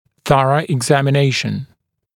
[‘θʌrə ɪgˌzæmɪ’neɪʃn] [eg-][‘сарэ игˌзэми’нэйшн] [эг-]тщательное обследование